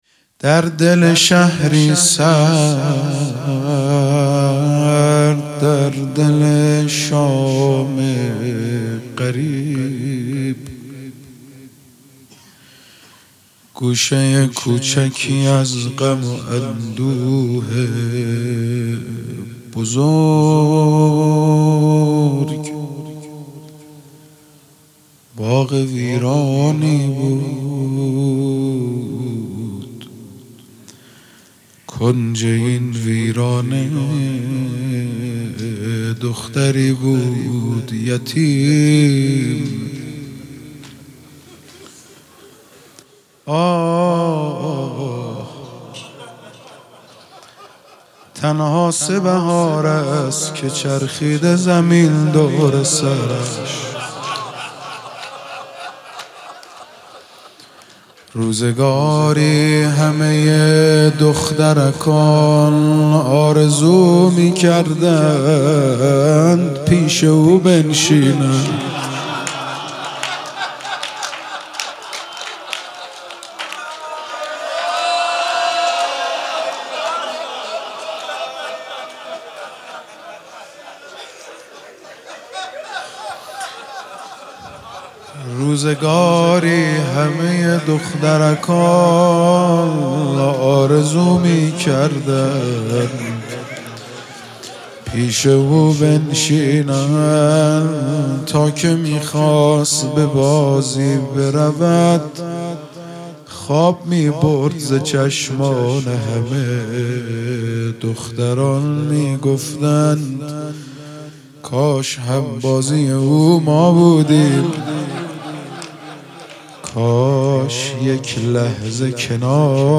سنتی ایرانی صدا